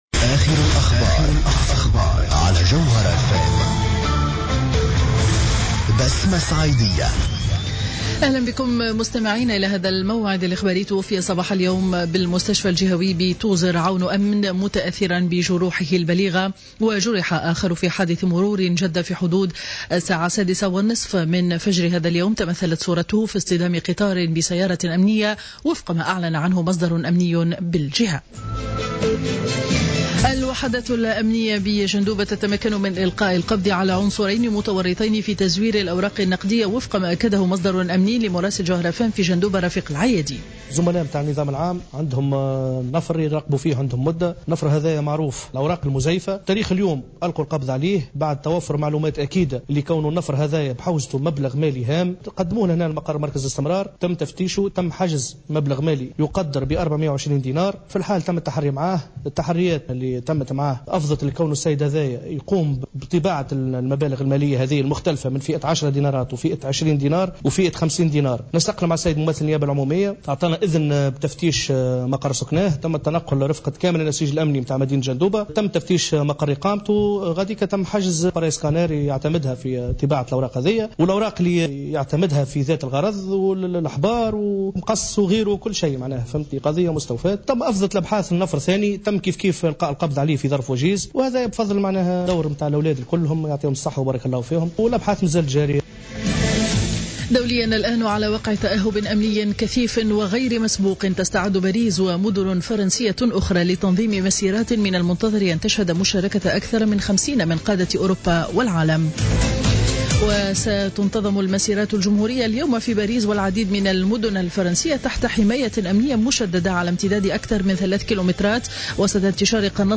نشرة أخبار منتصف النهار ليوم الأحد 11-01-15